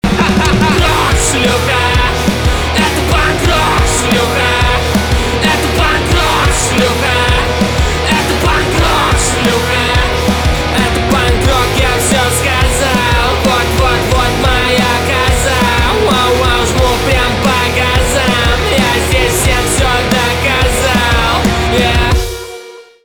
русский рок
гитара , барабаны , громкие